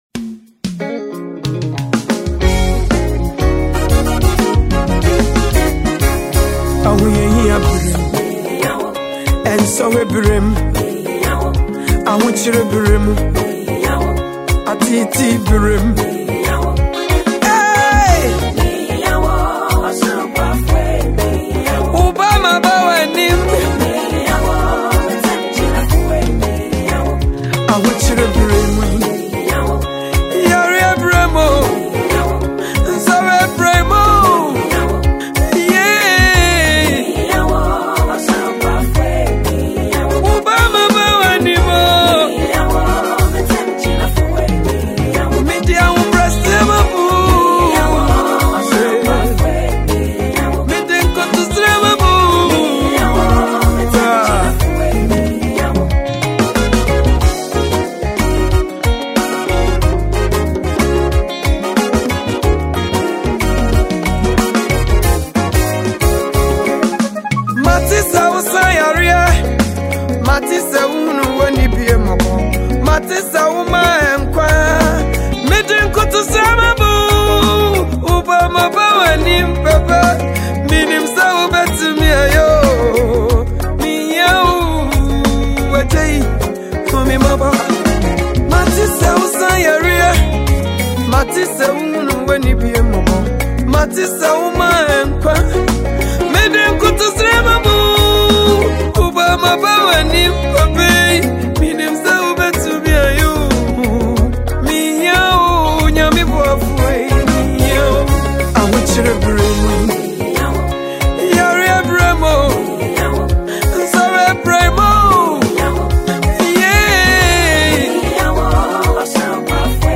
Gospel
powerful vocal delivery